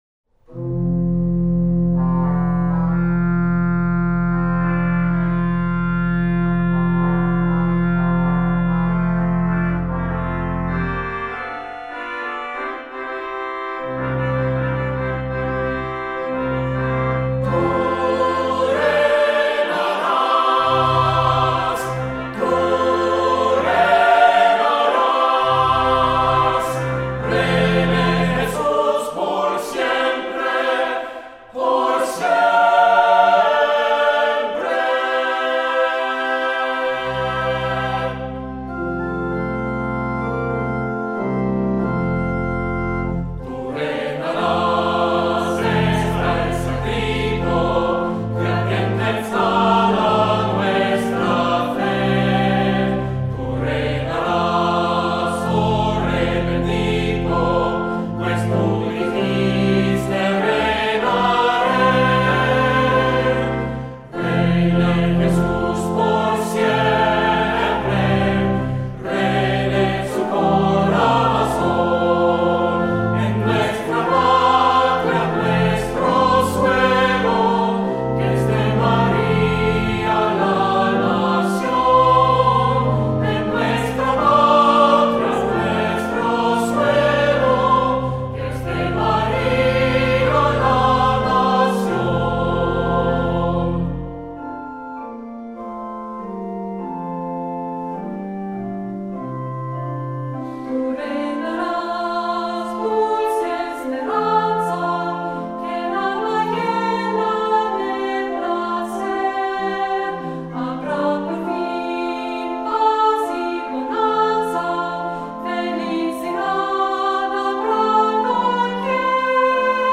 Voicing: Cantor, assembly, descant,SATB